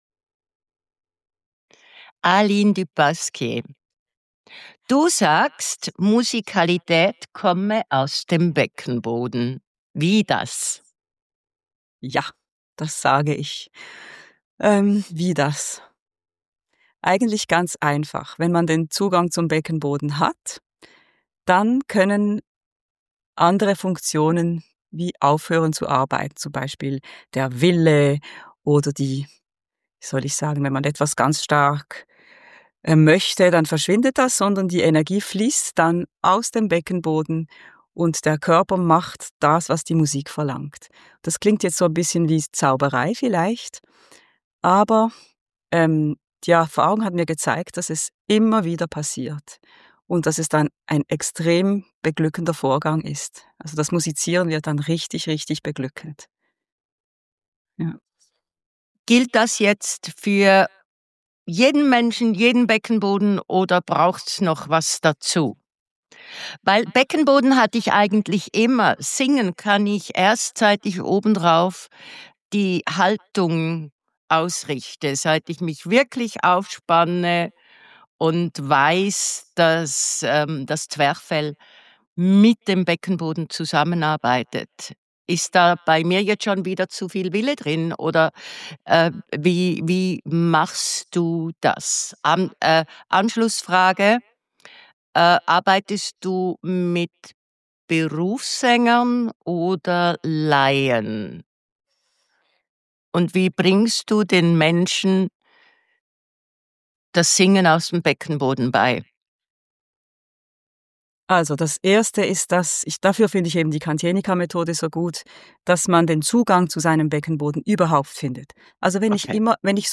und der Effekt in der Stimme der Sopranistin ist erstaunlich.